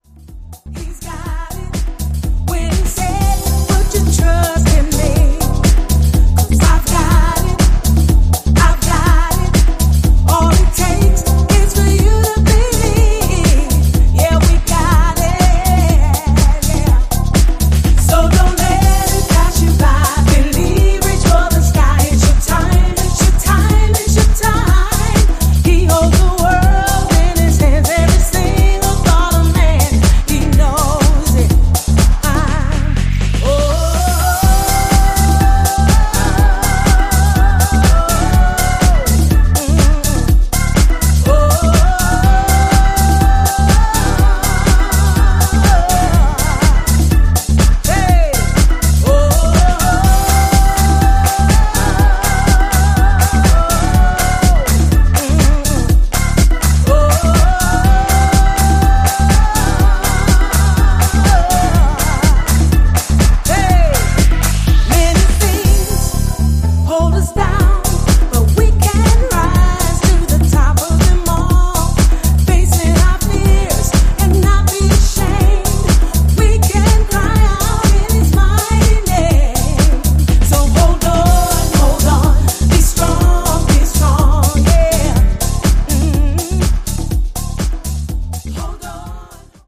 ジャンル(スタイル) SOULFUL HOUSE / DEEP HOUSE